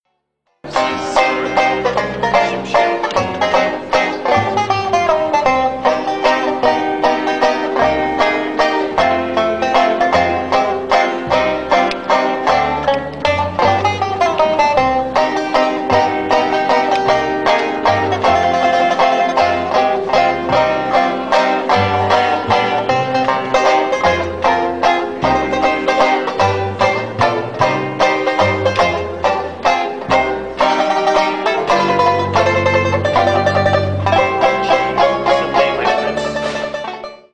16-beat intro.
This song is in 3/4 waltz time.